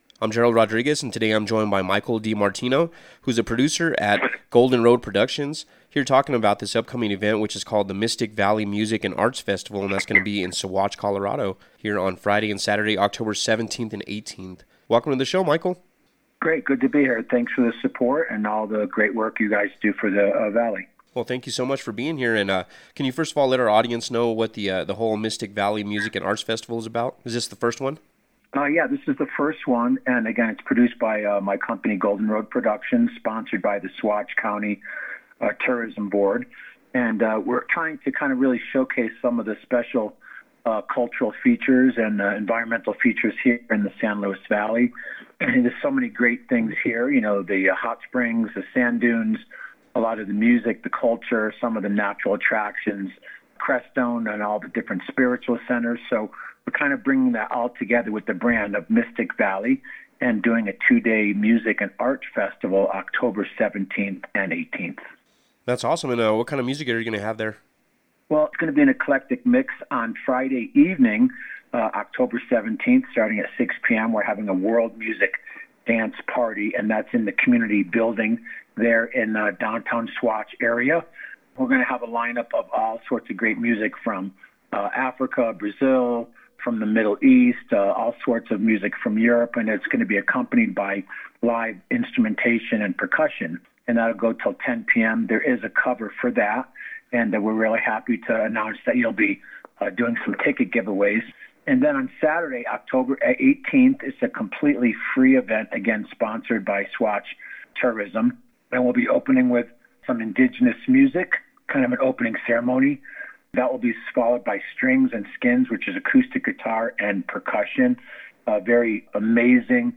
MysticValleyMusicandArtsFestInterview2025.mp3